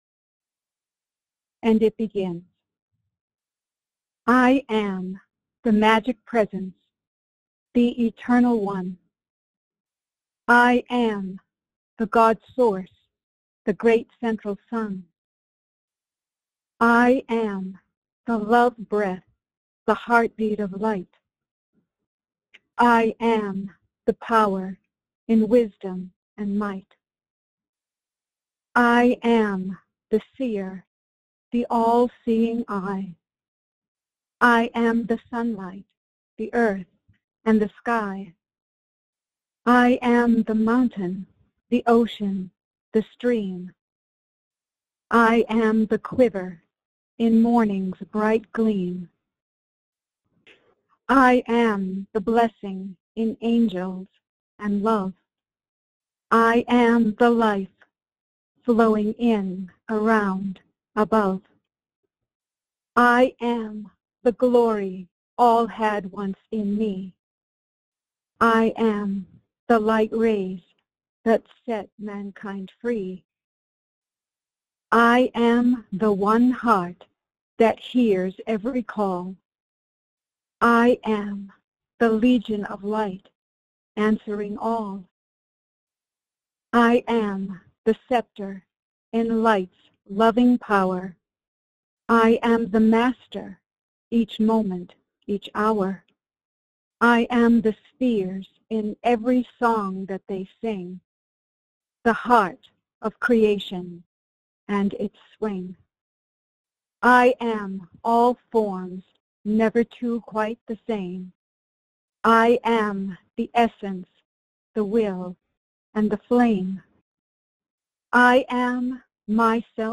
Audio Recording Meditation – Minute (00:00) Join Master Saint Germain in group meditation.